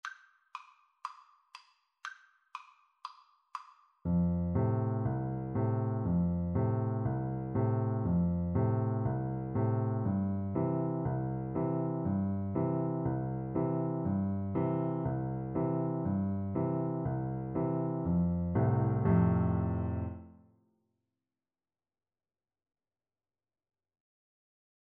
4/4 (View more 4/4 Music)
F major (Sounding Pitch) (View more F major Music for Piano Duet )
Molto Allegro (View more music marked Allegro)